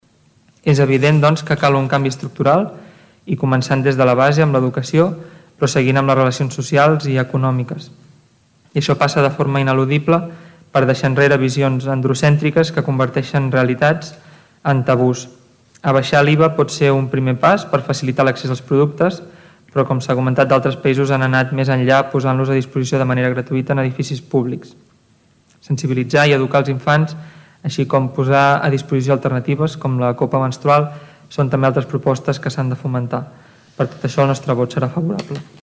La CUP, que va afegir que el masclisme també agreuja aquesta desigualtat, defensaven positivament aquesta moció. El regidor Oriol Serra demanava anar més enllà en algunes de les accions.